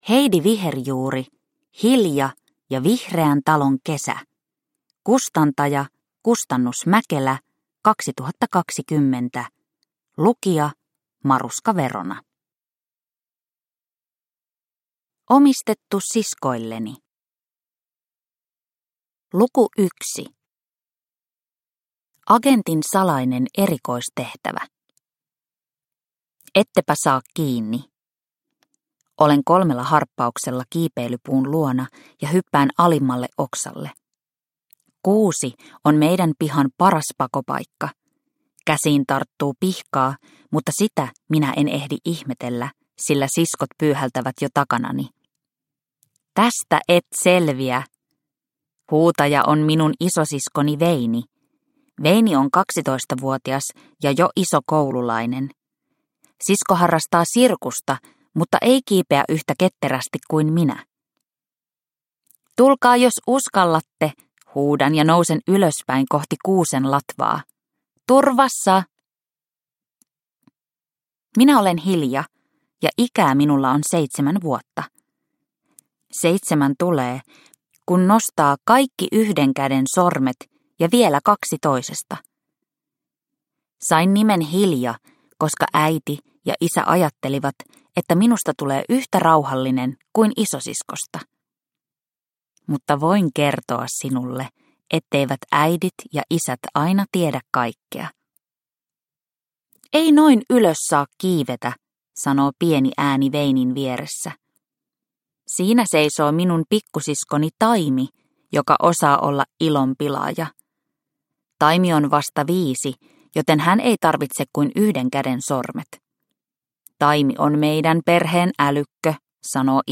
Hilja ja vihreän talon kesä – Ljudbok – Laddas ner